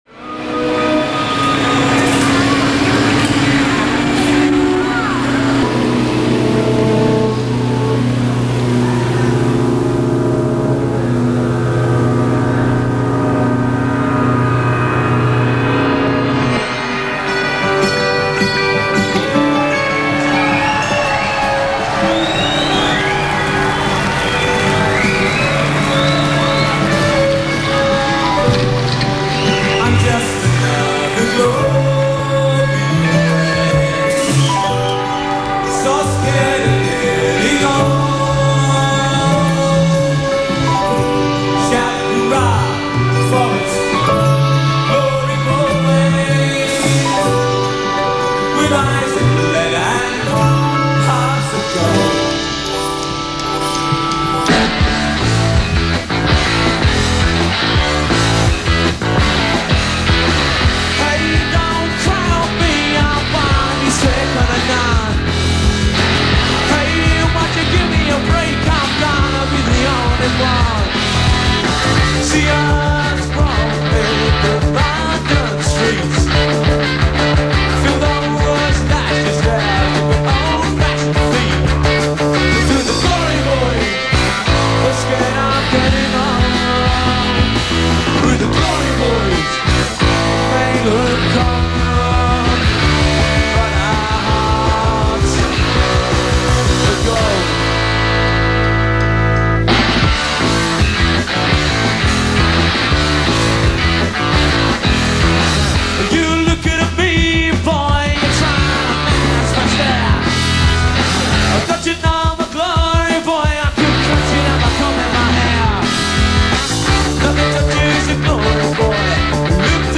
Ska/Mod Revival from the late 70s